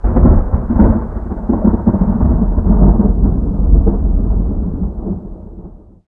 thunder12.ogg